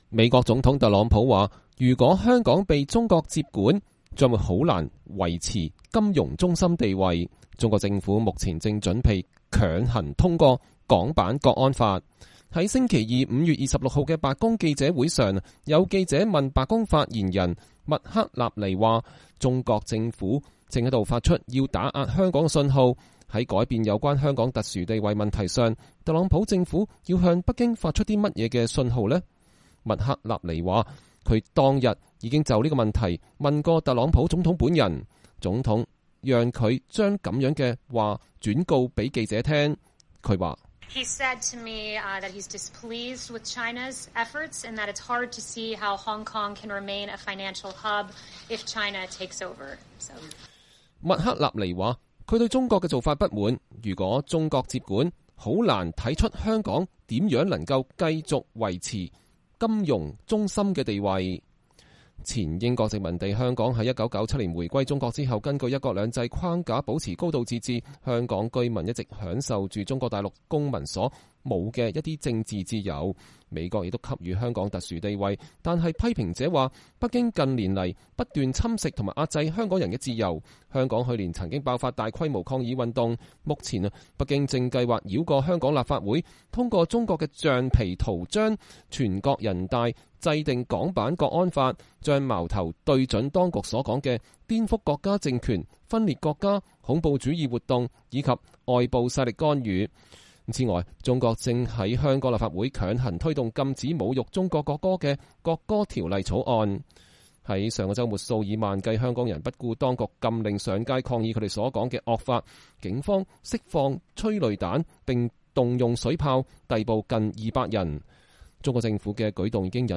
白宮新聞秘書凱莉麥克納尼在白宮記者會上講話。(2020年5月26日)